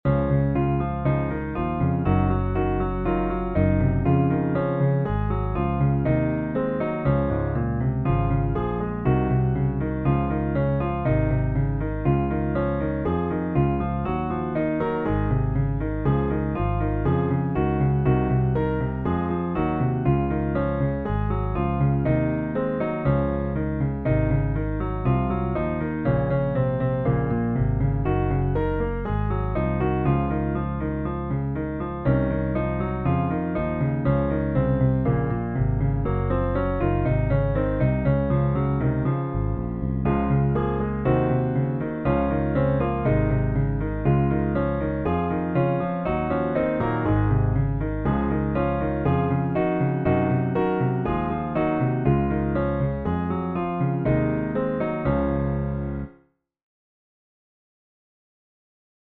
장서교통공사로고송.mp3